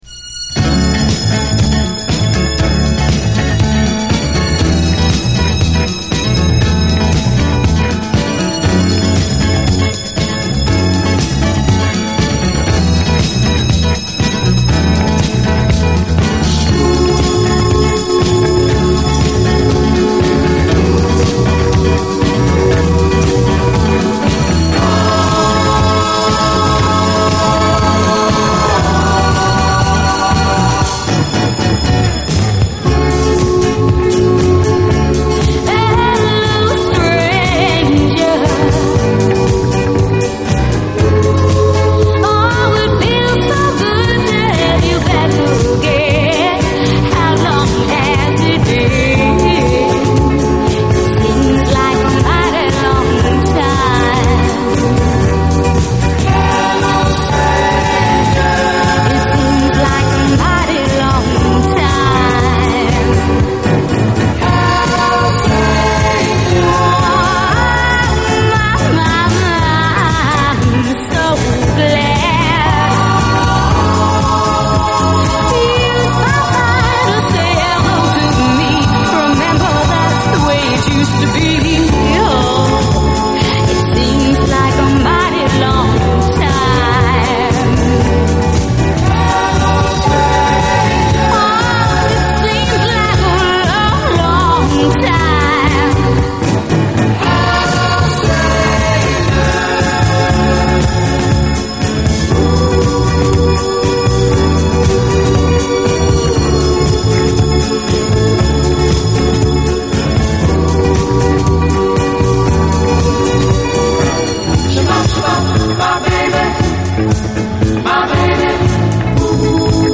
SOUL, 70's～ SOUL, DISCO
スウィート＆エレガント＆ドリーミーな原曲のテイストはそのままに！